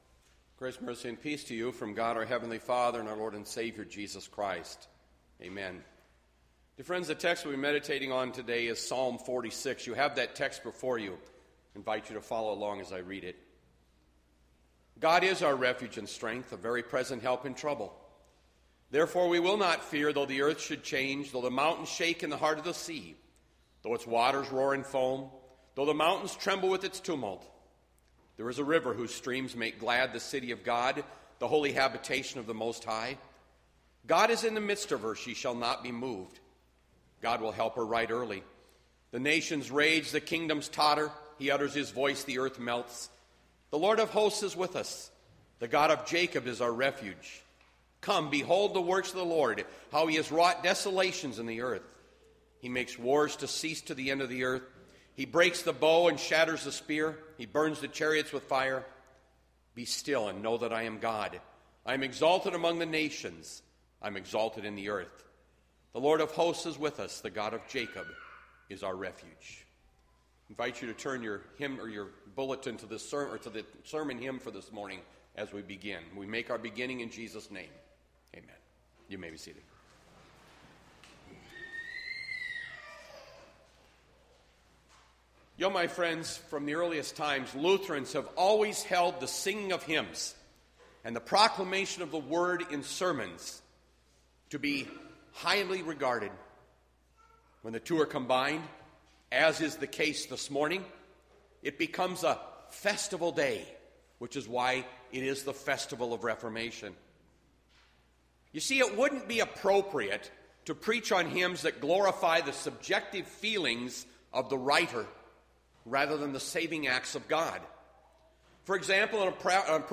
Bethlehem Lutheran Church, Mason City, Iowa - Sermon Archive Oct 25, 2020